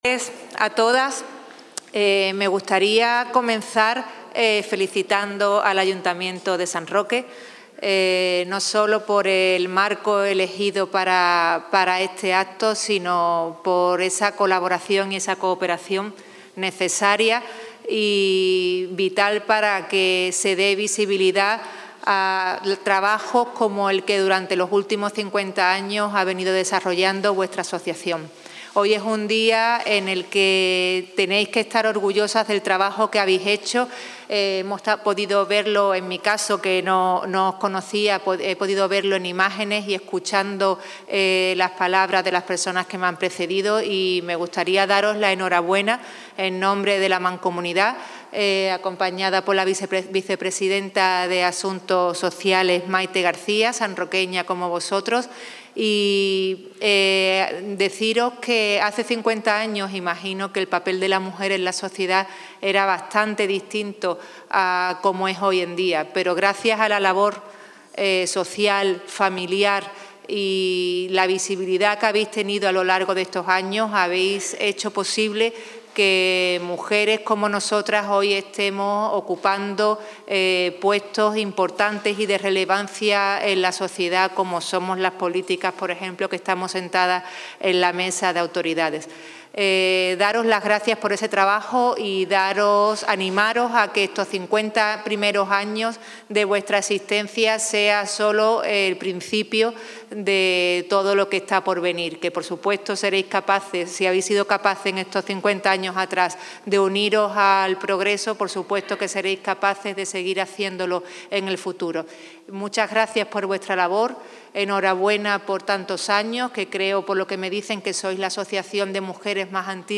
ANIVERSARIO CONSTANCIA (TOTAL PTA MANCOMUNIDAD) 5 MARZO 2026.mp3